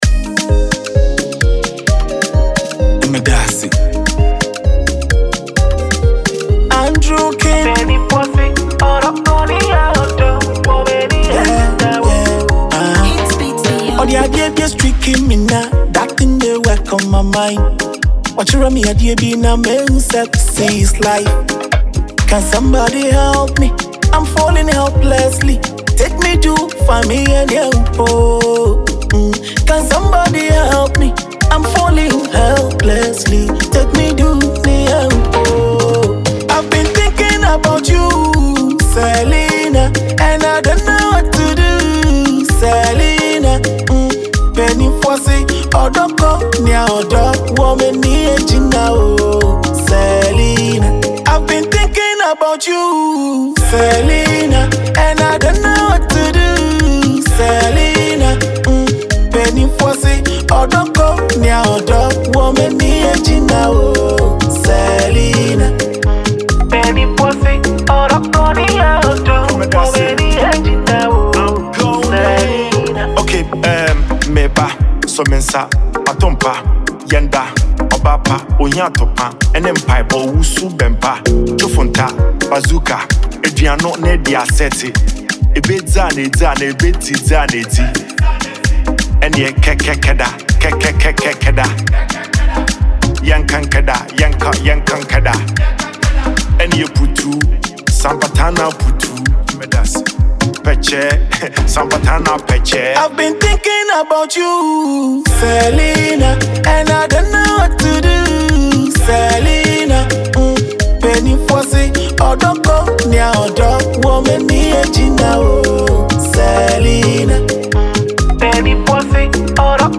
Ghana Music
Smooth and Vulnerable Love Anthem
mid-tempo Afro-pop record
Produced with lush synth pads and a rhythmic Afrobeat bounce
soulful vocals on the hook
rhythmic, playful rap verses